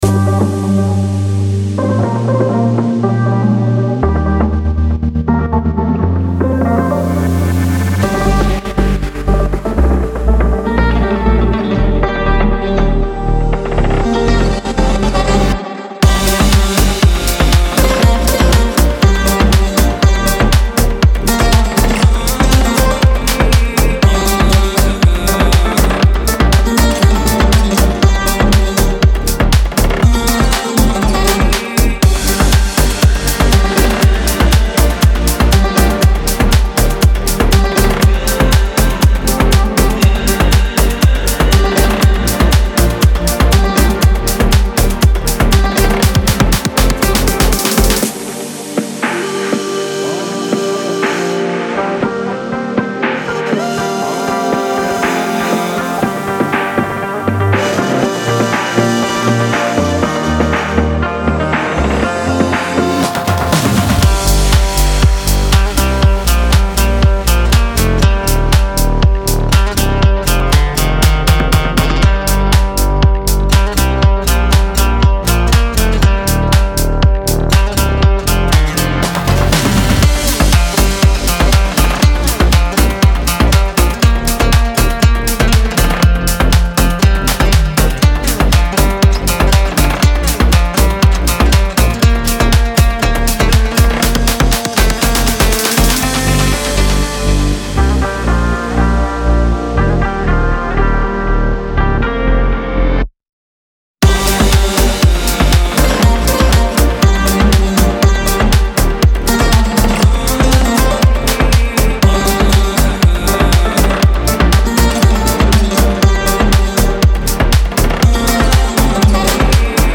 Музыкальная мелодия
Без слов
Манящая и интригующая